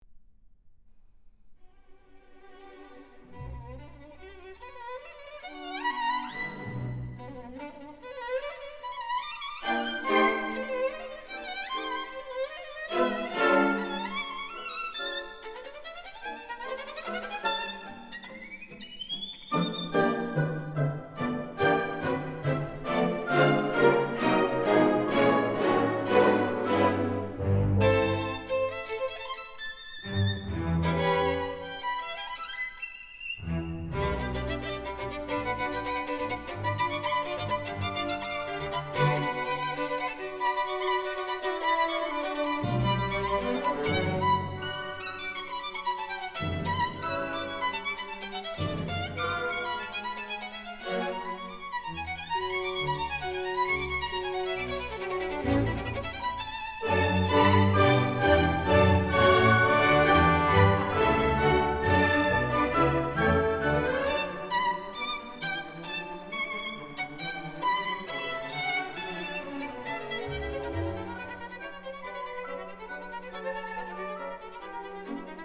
古典音樂
Concerto In B Minor For Violin And Orchestra op.61